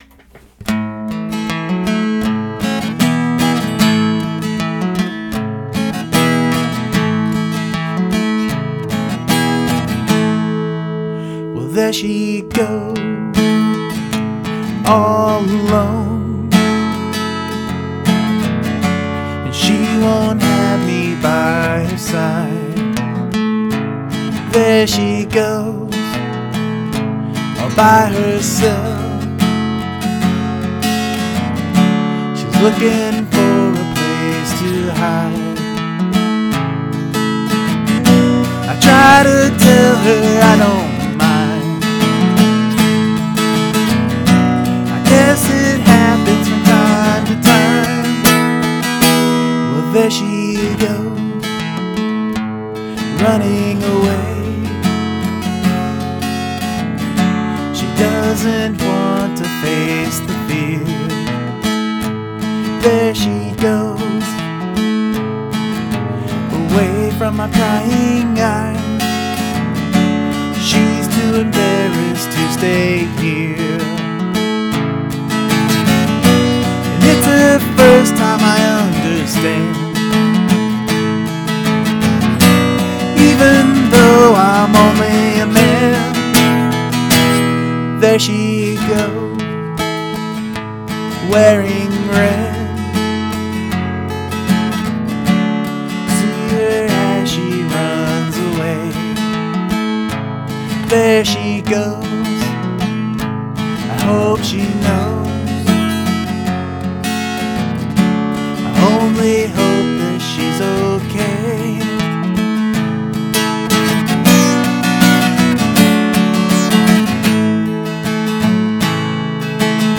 Acoustic: yamaha acoustic/electric
Vox: AT2020
Recorded on: BR900-CD
Sorry for the distortion.
:)  I enjoyed the change-up into the chorus. Good vocals as well.